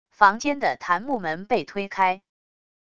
房间的檀木门被推开wav音频